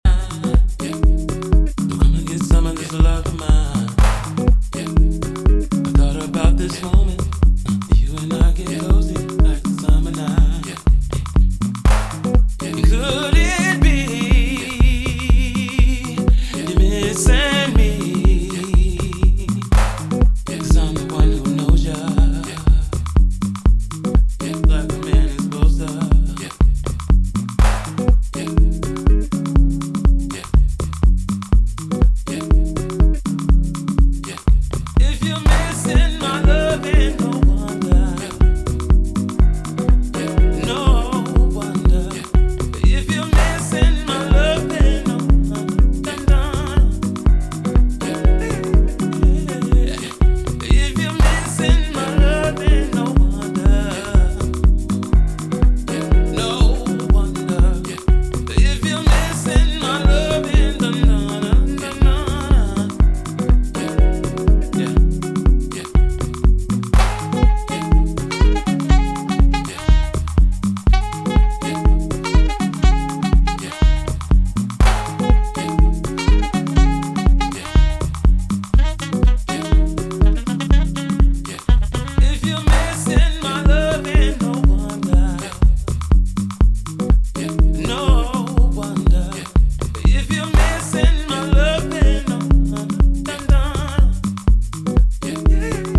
温かみのあるコードやメロディーを加えながら、じんわり沁み込む味わいが増したヴォーカル・ハウスに仕上げています。